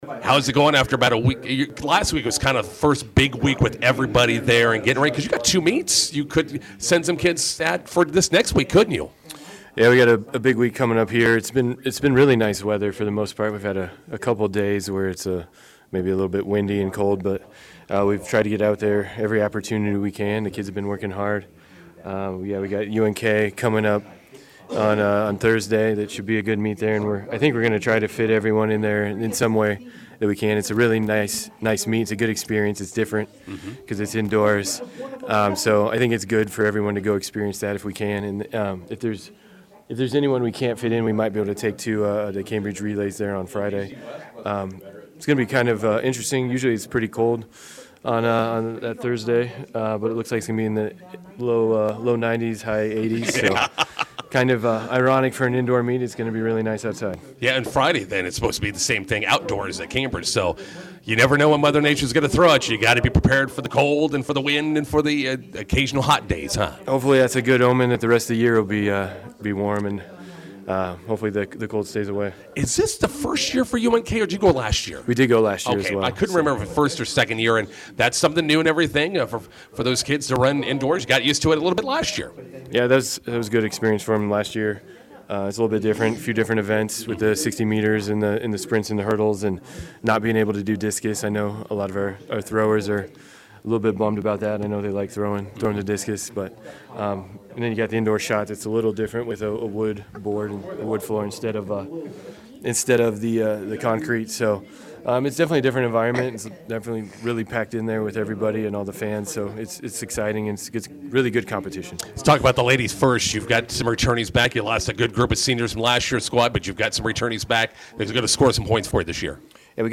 INTERVIEW: Southwest Track and Field kicks off their spring season Thursday at UNK Indoor.